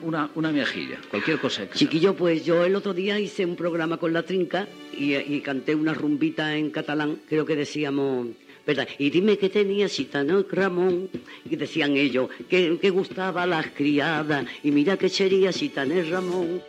Fragment d'una entrevista a Lola Flores que canta un fragment de rumba catalana
Info-entreteniment